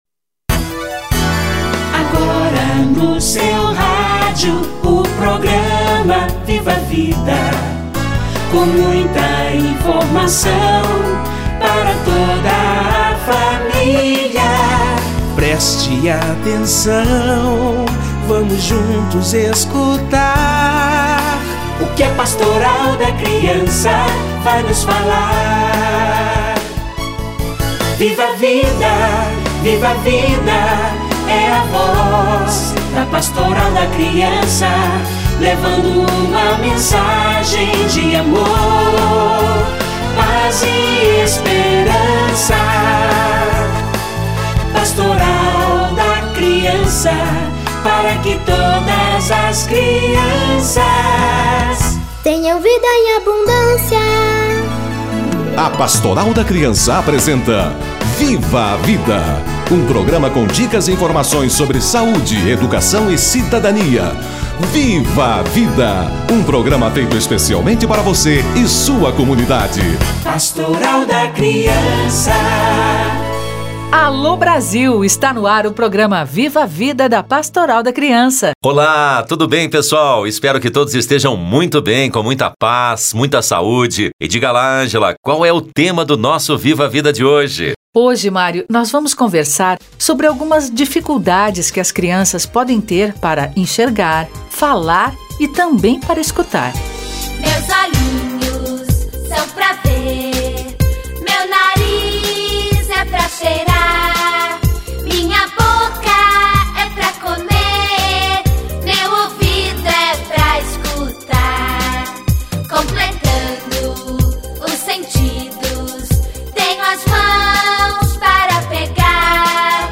Dificuldades de visão - fala e audição na infância - Entrevista